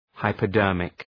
Shkrimi fonetik{,haıpə’dɜ:rmık}